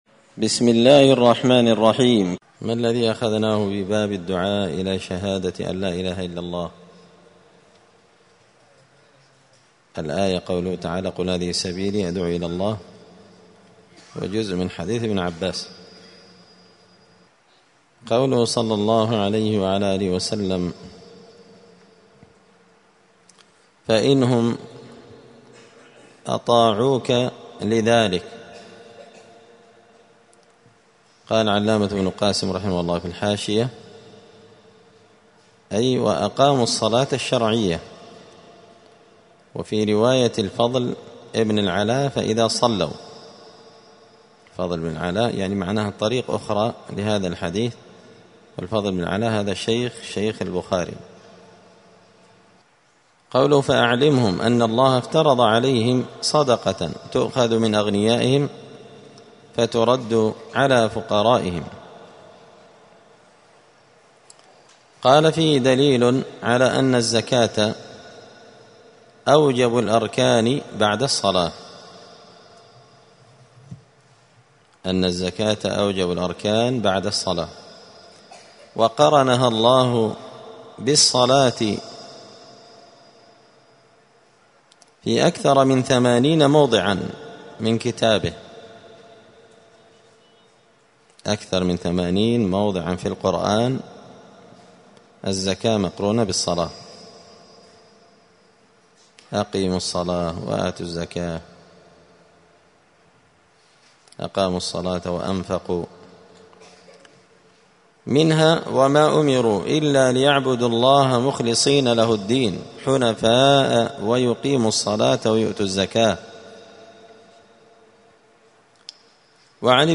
دار الحديث السلفية بمسجد الفرقان بقشن المهرة اليمن
*الدرس الحادي والعشرون (21) {تابع للباب الخامس باب الدعاء إلى شهادة أن لا إله إلا الله…}*